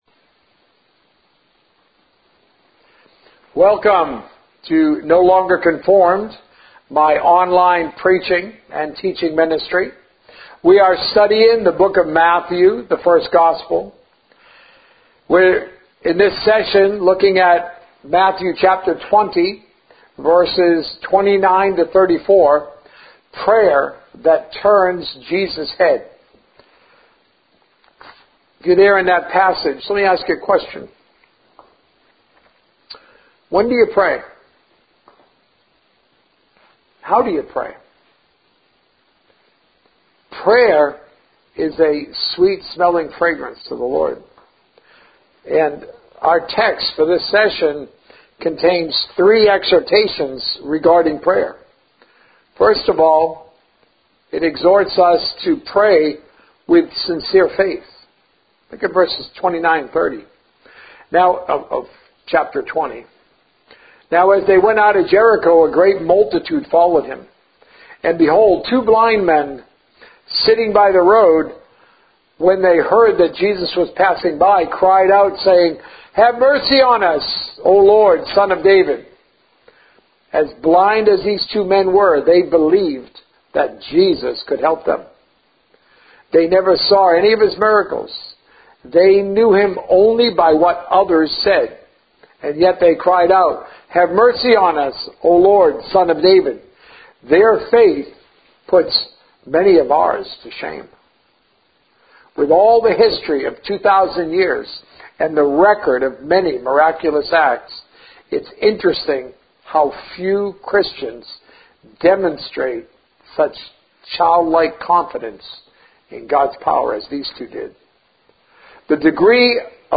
A message from the series "The First Gospel."